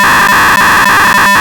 Cospas Sarsat 406 Mhz Emergency Beacon [ pts]
As we can see the signal is sent as short burst with a interval like in the photo, which translated as a series of ‘0’ in decoded.
ph0wn-beacon.wav